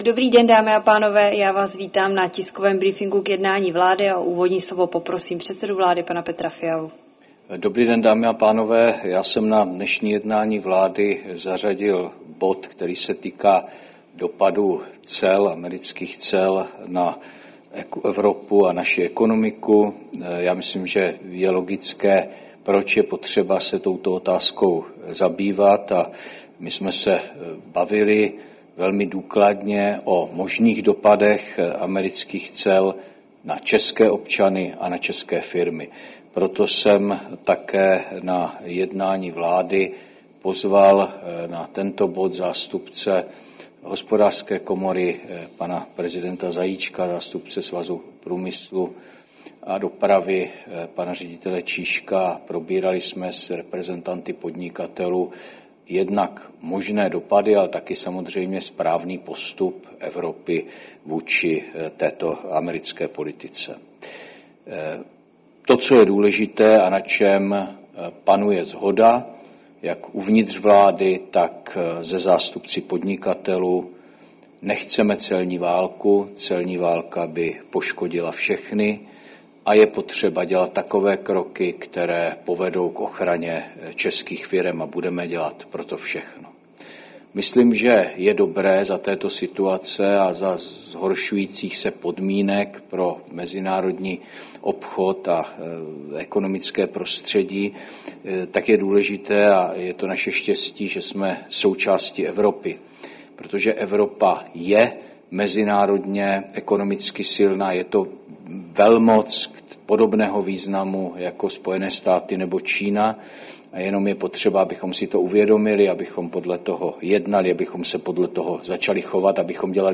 Tisková konference po jednání vlády k dopadům nových amerických cel, 9. dubna 2025